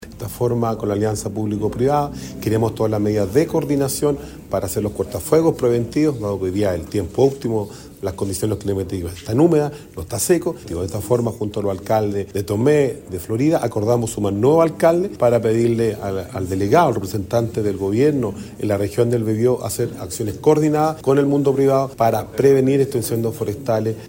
Es por eso que Rodrigo Vera, alcalde de Penco, insistió en que como ya está pasando el invierno y las temperaturas subirán, los jefes comunales están preocupados y, por ello, crearon la asociación preventiva de incendios forestales del Gran Concepción.